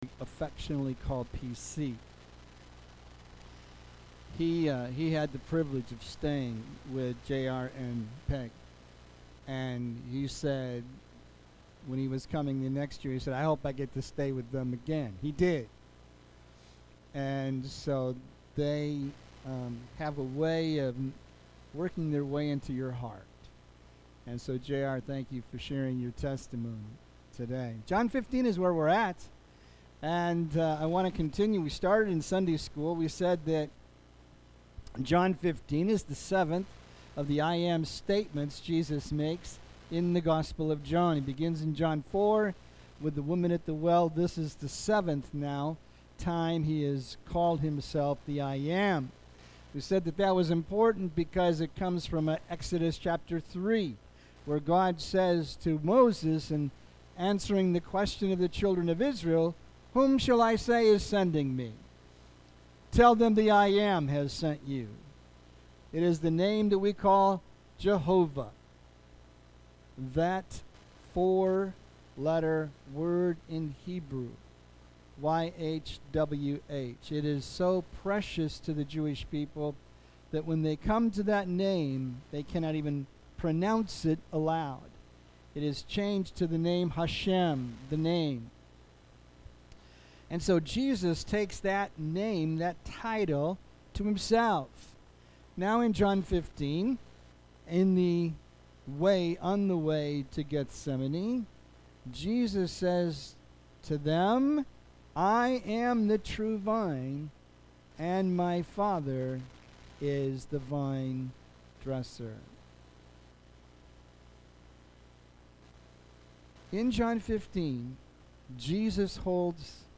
A message from the series "Guest Speakers."